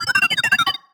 sci-fi_driod_robot_emote_beeps_08.wav